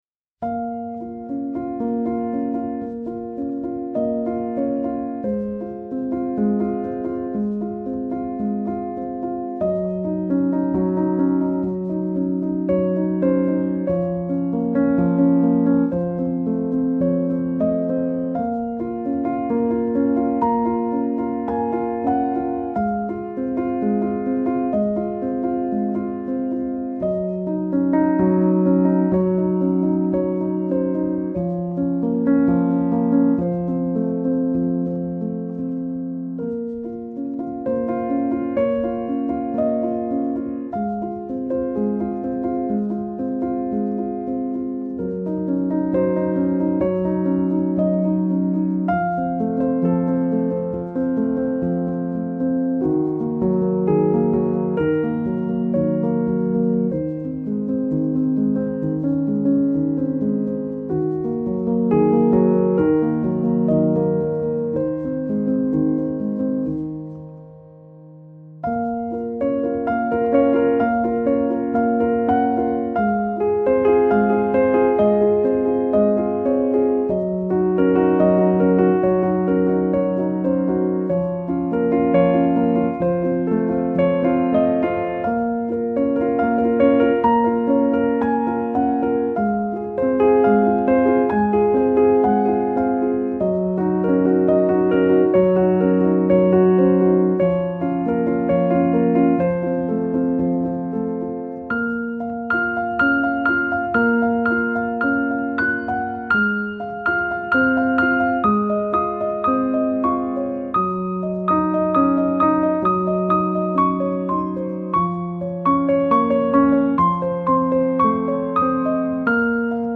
آرامش بخش
Classical Crossover
پیانو